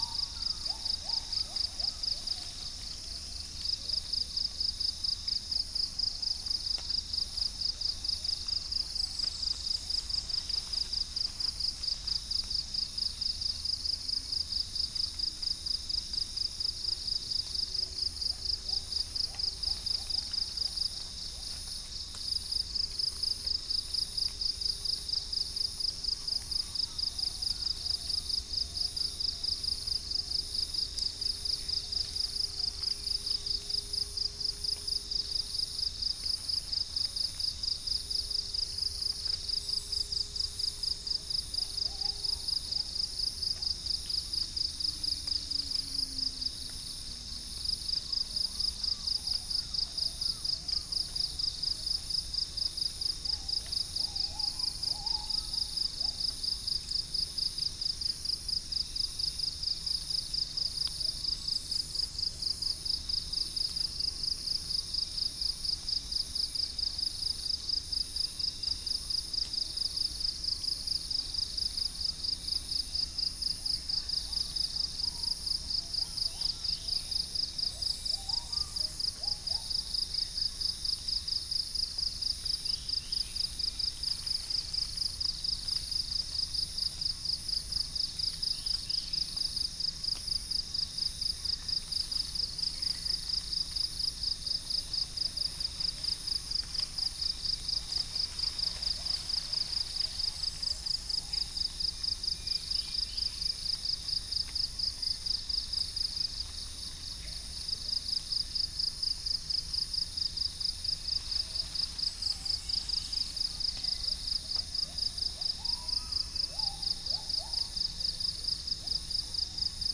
Upland plots dry season 2013
Macronus ptilosus 1607 | Stachyris maculata
Gallus gallus domesticus
Centropus sinensis